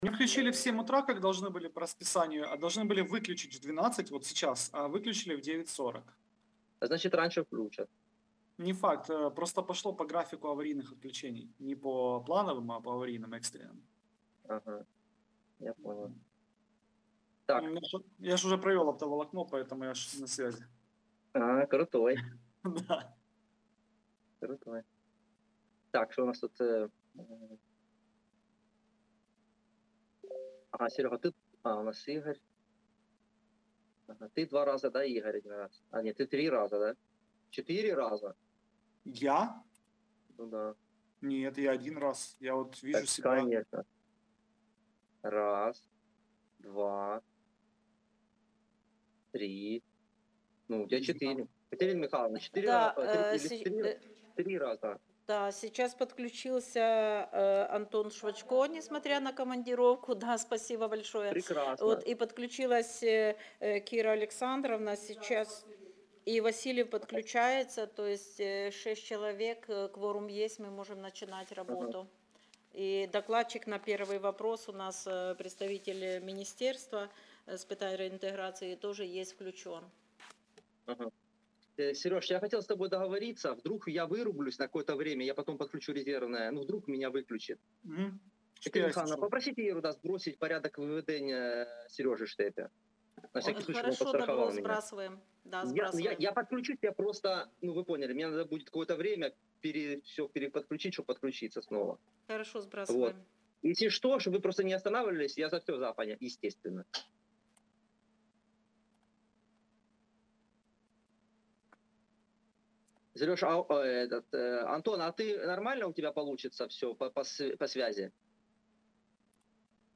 Аудіозапис засідання Комітету від 06.12.2022